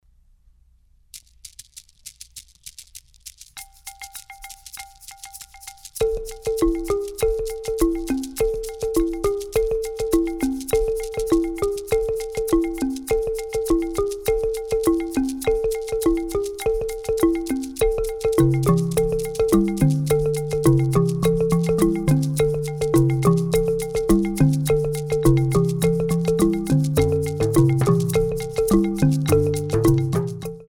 Based on Traditional gyil melody